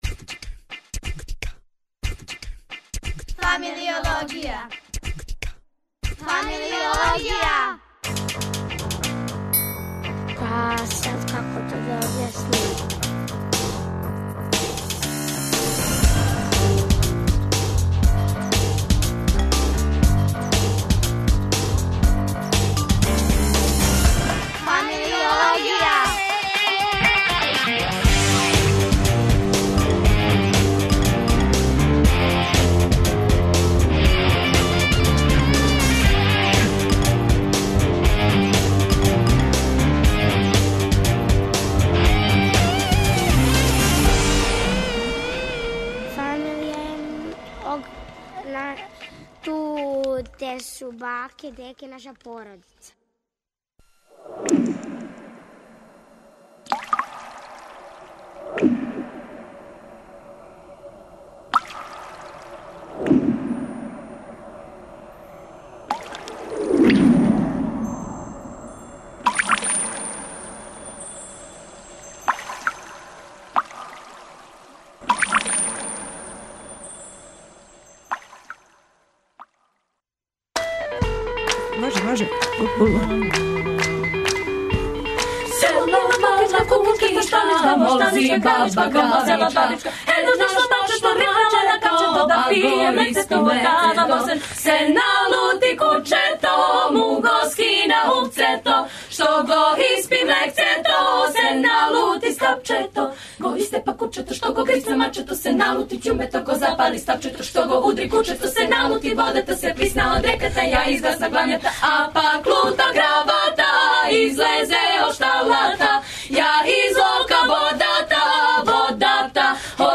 У делу емисије 3 пута 3 говоримо, дакле, о тинејџерима, уз вест о новим открићима о развоју мозга (много дуже се развија него што се до сада мислило). Још говоримо и о храни и о новом Стоунхенџу... А у делу емисије ПЛУС, угостићемо хор девојака из Пожаревачке гимназије.